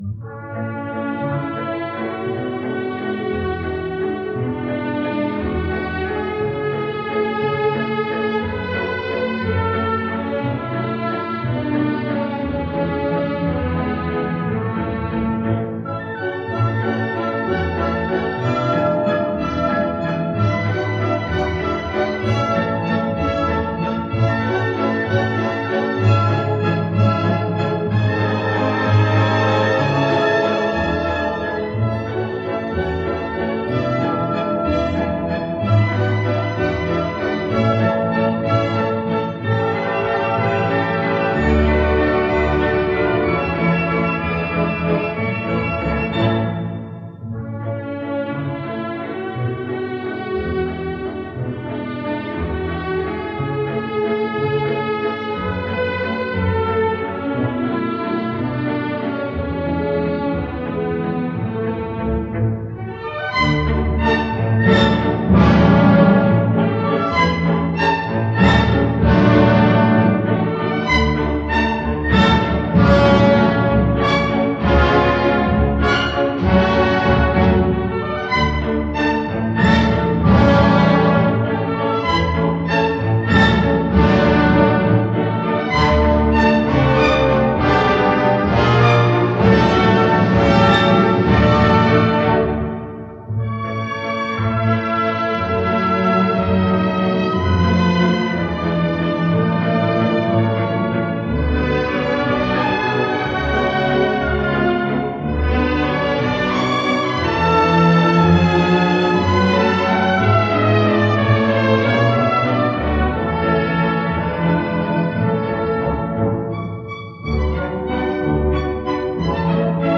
Gran Orchesta Vienesa de Conciertos “Skaters Waltz.”
12-the-skaters-waltz-2.mp3